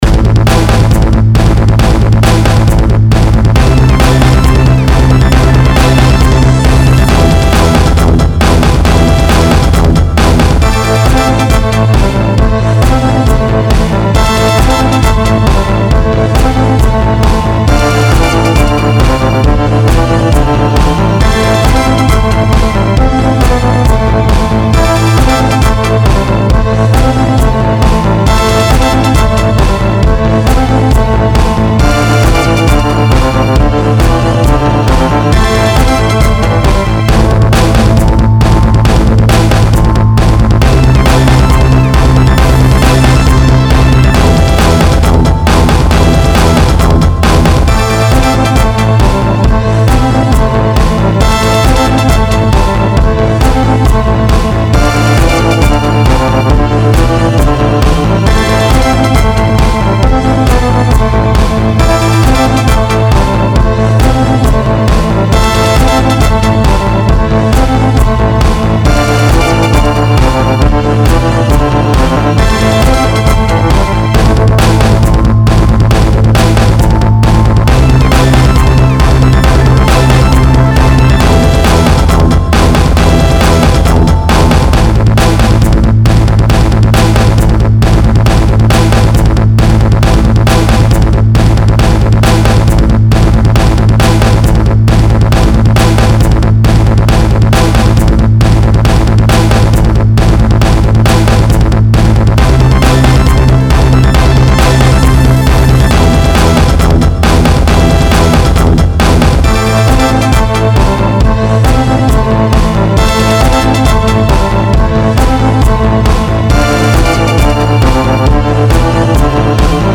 [NOTE: This is the "basic rhythm section" done in Notion 3 for yet another fabulous song about ladies underpants, which to be specific is my Miley Cyrus underpants song, and it is an extravaganza of rapid double-kick drumming, which is a lot easier than taking a few months to teach myself how to do it on the Really Bigger Drumkit™ using a pair of Dualist D4 Single-Foot Double-Pedal units .